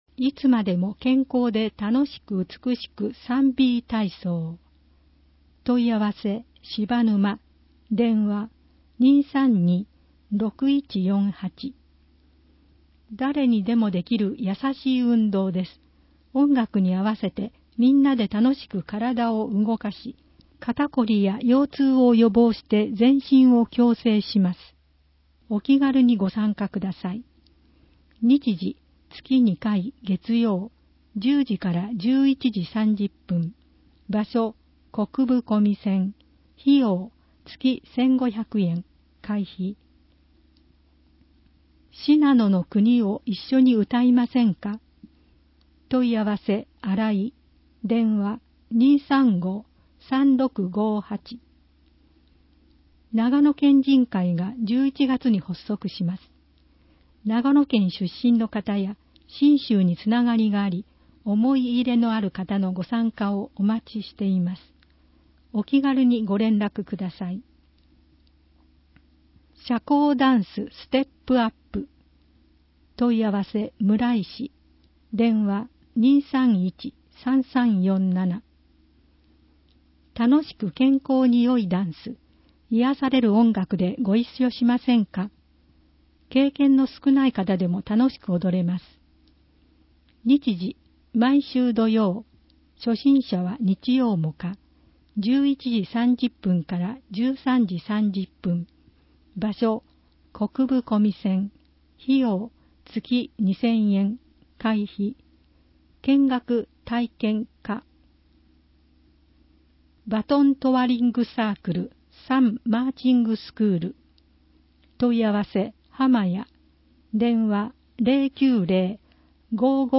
※音声版は、音声訳ボランティア「矢ぐるまの会」の協力により、同会が視覚障がい者の方のために作成したものを、順次搭載します。